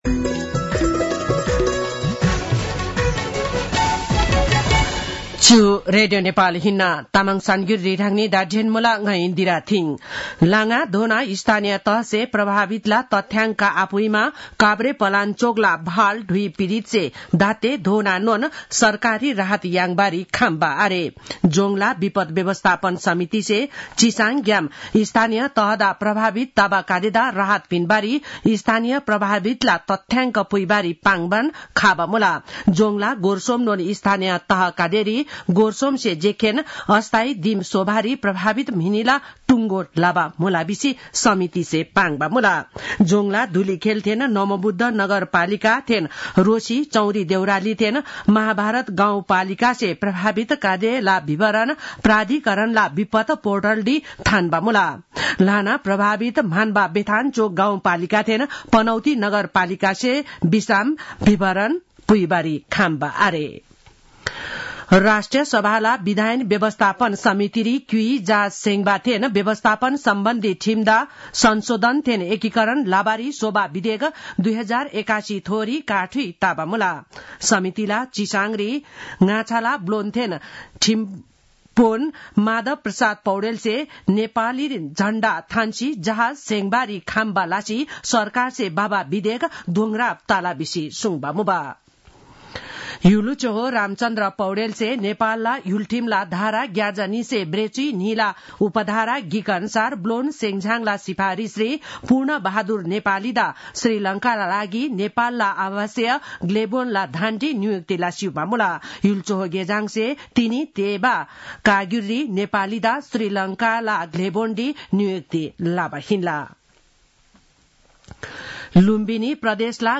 तामाङ भाषाको समाचार : २० फागुन , २०८१